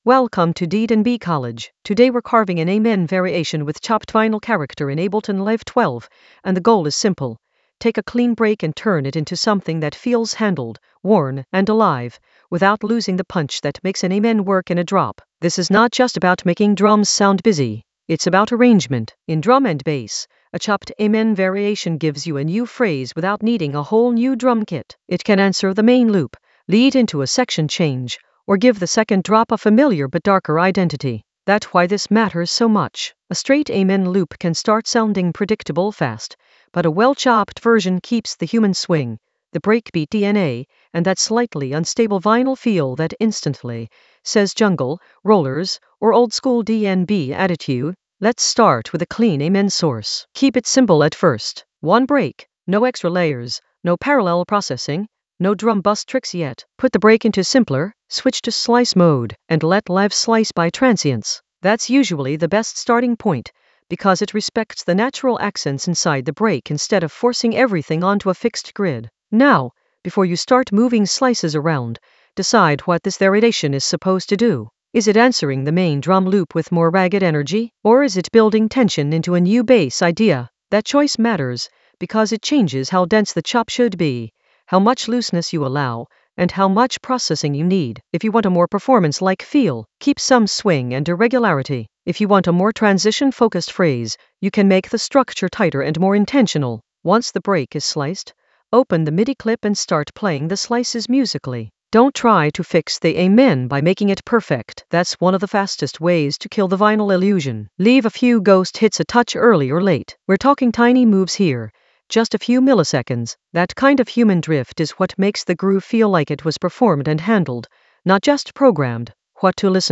An AI-generated intermediate Ableton lesson focused on Carve an amen variation with chopped-vinyl character in Ableton Live 12 in the Arrangement area of drum and bass production.
Narrated lesson audio
The voice track includes the tutorial plus extra teacher commentary.